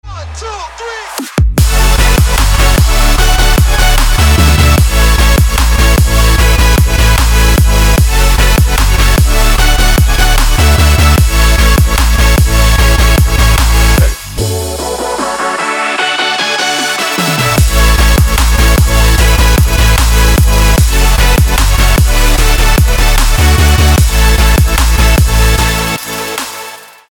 • Качество: 320, Stereo
dance
Electronic
EDM
Trap
Стиль: Chill Trap